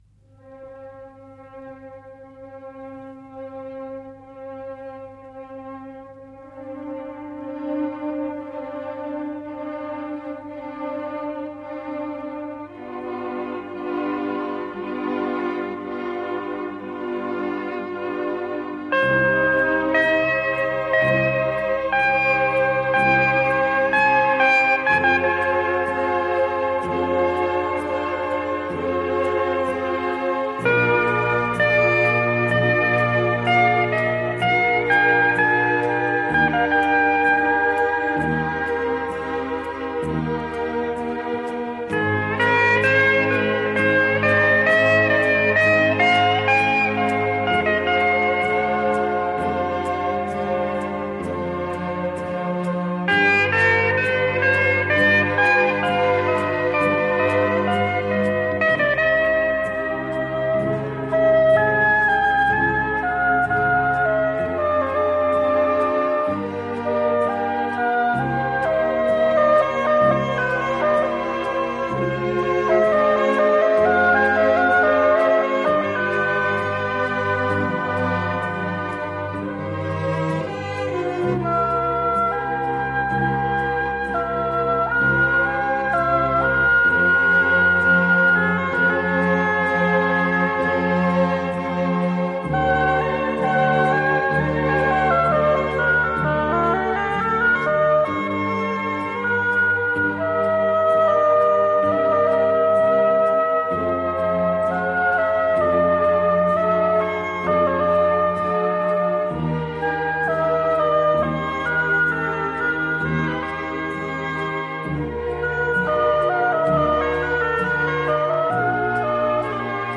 radiomarelamaddalena / STRUMENTALE / GUITAR HAWAY / 1 /